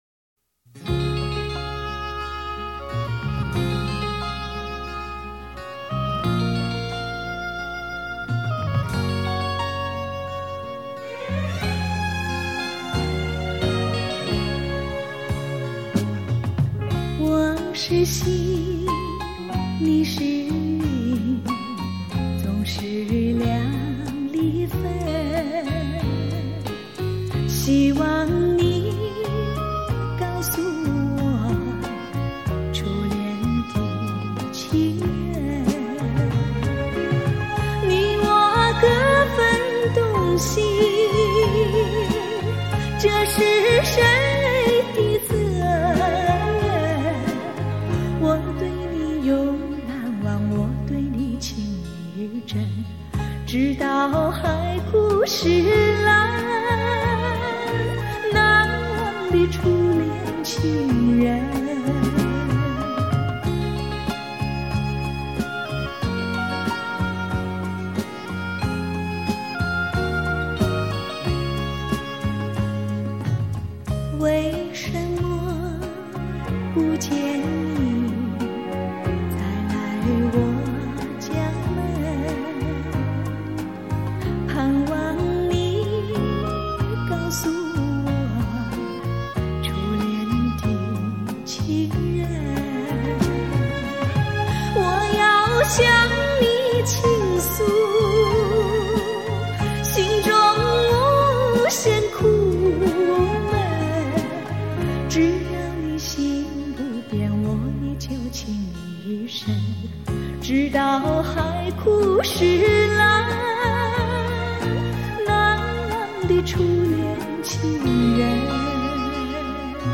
怀旧的歌声，让时空倒转，让身心放松，细细的品味，静静的聆听，永恒的情怀，美好的回忆，听昨日之歌。